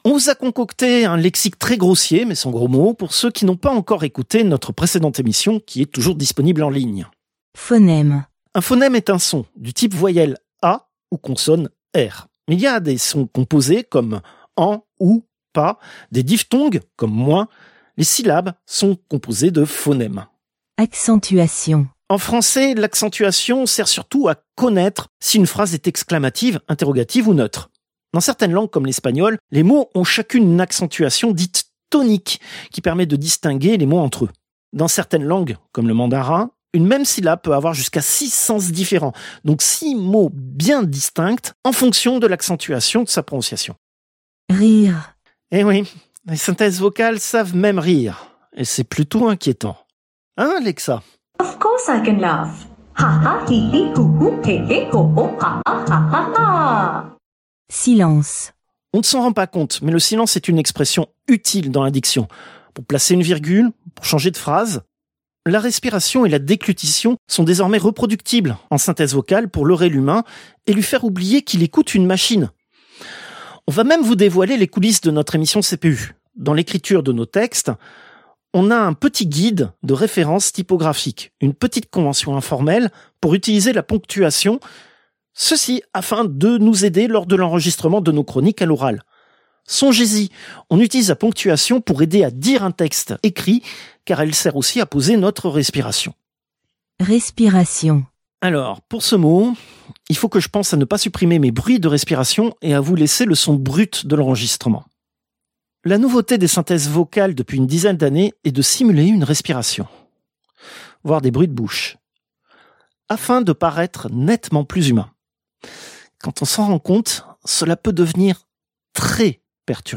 Extrait de l'émission CPU release Ex0225 : Synthèse vocale, seconde partie.